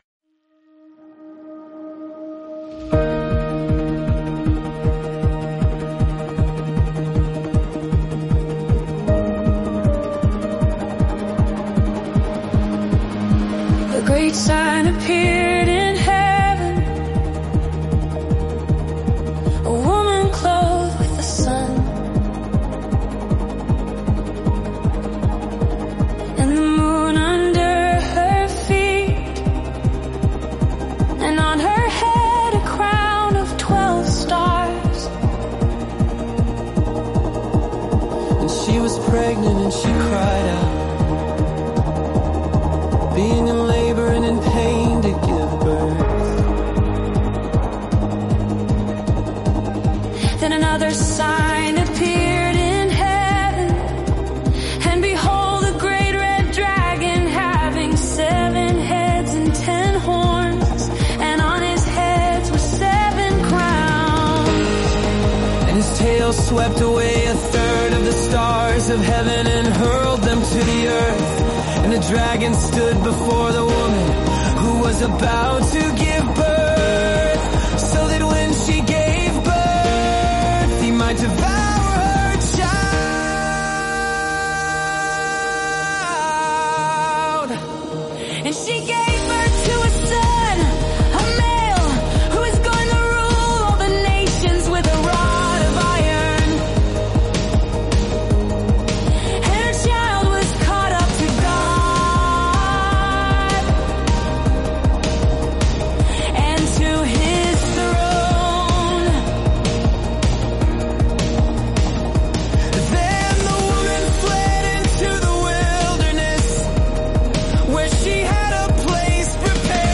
Experience the awe-inspiring visions and promises of Revelation in just 7 days through word-for-word Scripture songs. Each day, listen to passages that reveal God’s ultimate victory, the hope of eternity, and His unwavering faithfulness—brought to life through music.